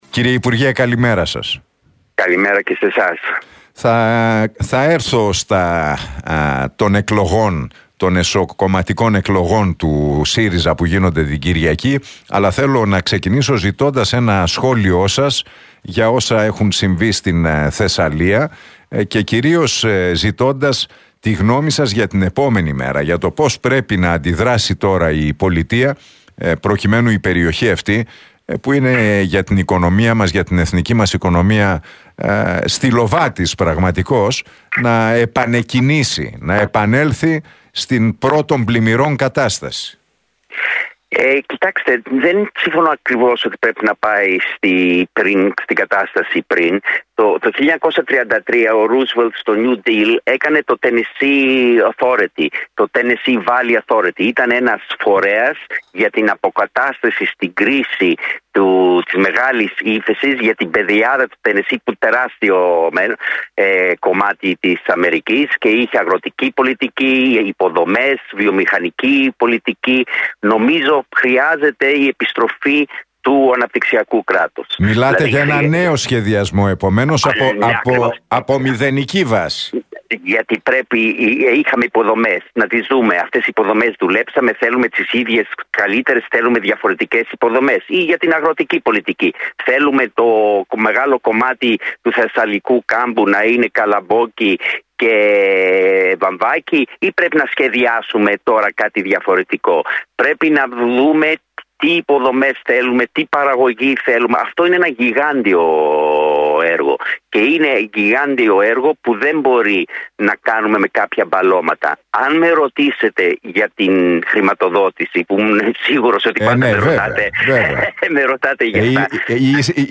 Ο Ευκλείδης Τσακαλώτος μίλησε στην εκπομπή του Νίκου Χατζηνικολάου στον Realfm 97,8 τόσο για την κακοκαιρία που έπληξε την Θεσσαλία αλλά και τις εσωκομματικές